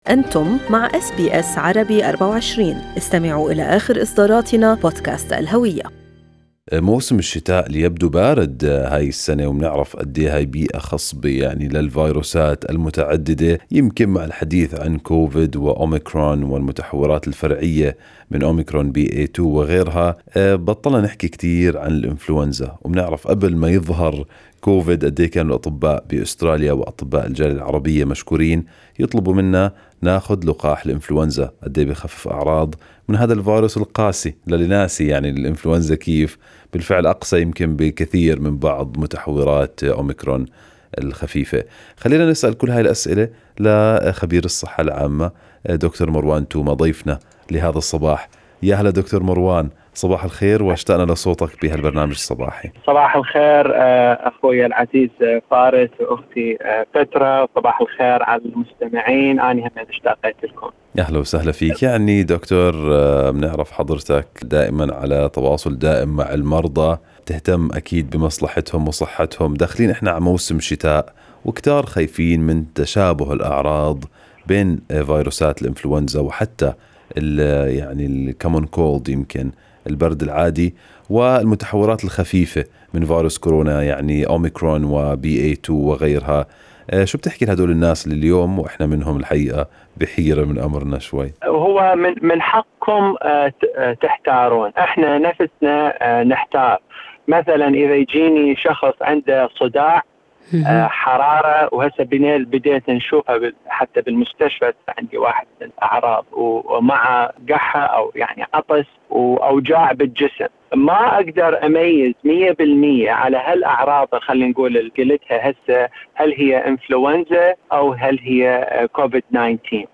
طبيب الصحة العامة